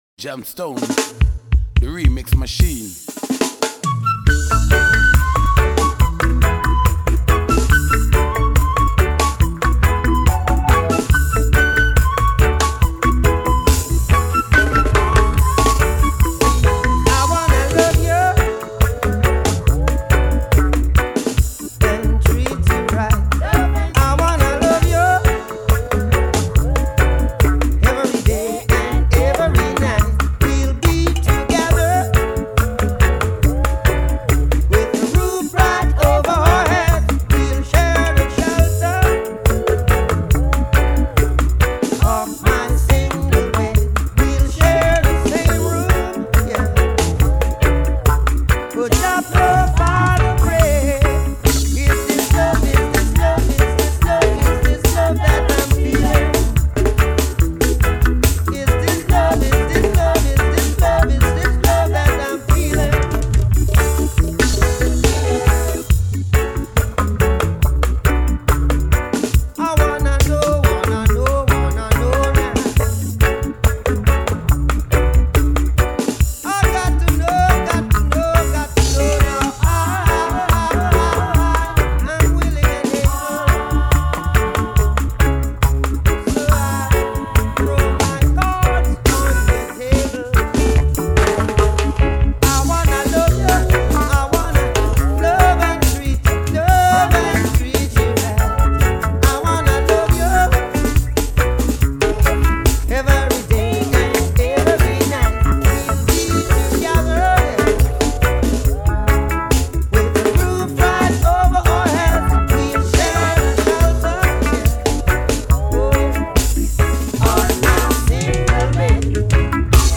Jungle riddim